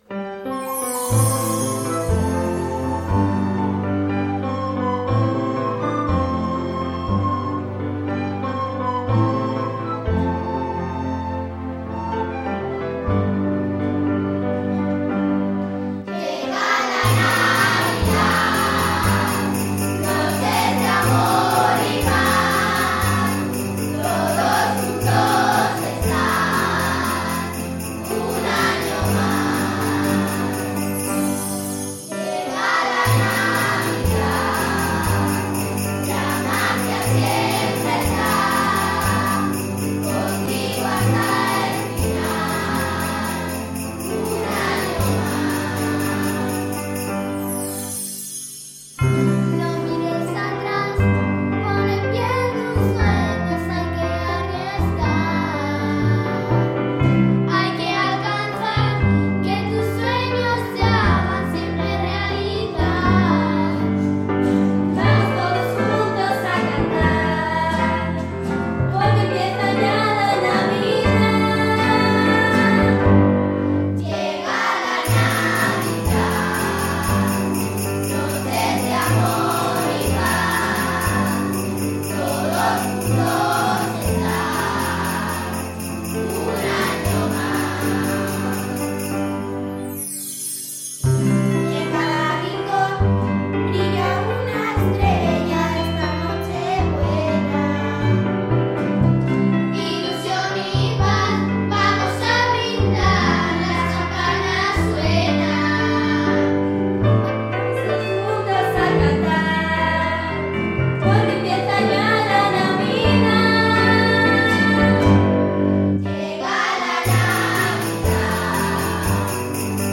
Participamos con nuestro villancico "Llega la navidad" en el concurso de villancicos de Cadena 100
mp3_villancico_llega_la_navidad1.mp3